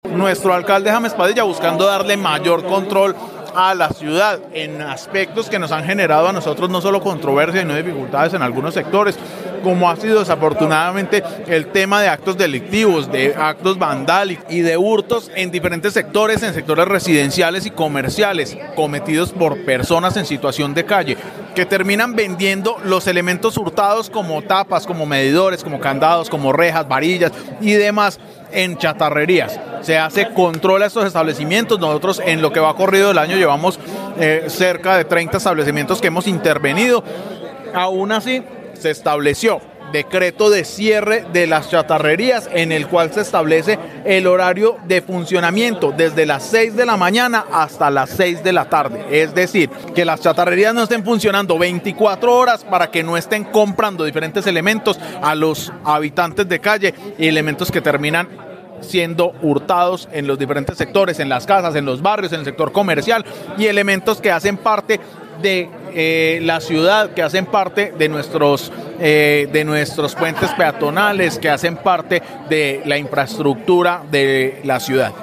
Andrés Buitrago, secretario de gobierno de Armenia